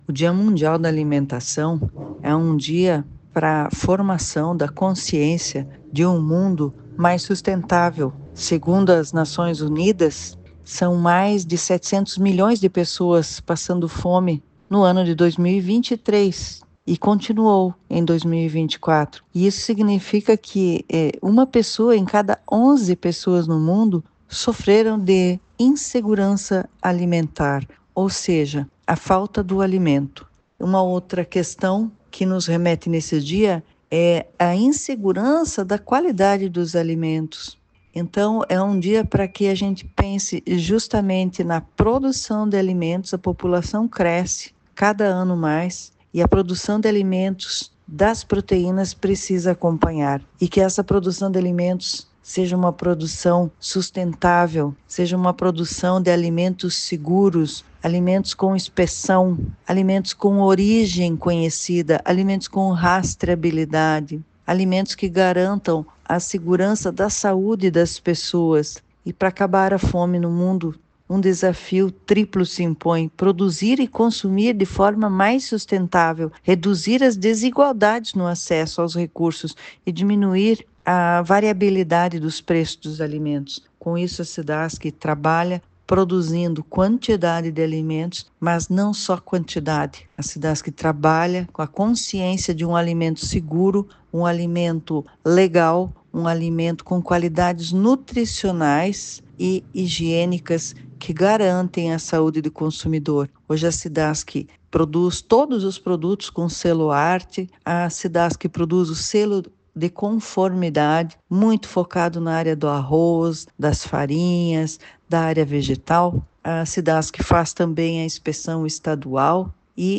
A presidente da Cidasc, Celles Regina de Matos, fala sobre o trabalho desenvolvido pela Companhia para garantir alimentos seguros e de qualidade para os consumidores:
SECOM-Sonora-Presidente-Cidasc-Dia-Mundial-Alimentacao.mp3